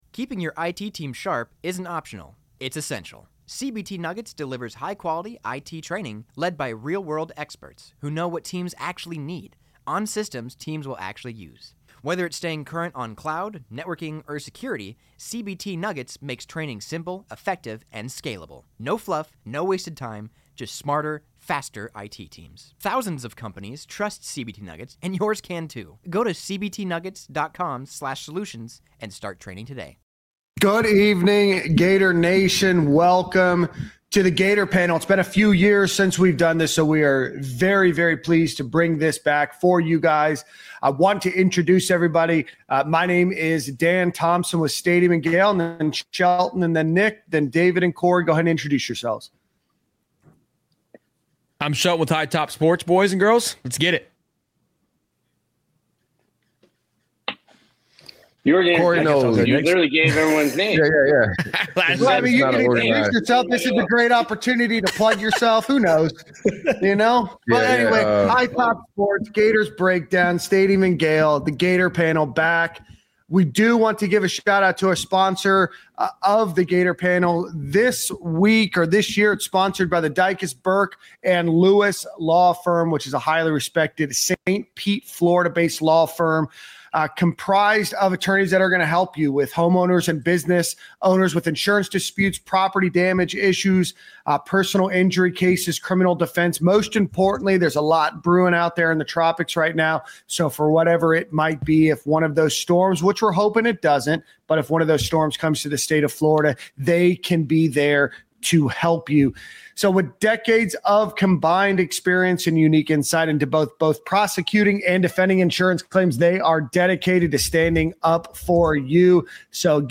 Join us for an in-depth panel discussion covering everything from DJ Lagway’s Heisman buzz and Billy Napier’s “championship-caliber” defense, to a brutal six-game gauntlet that could define the year.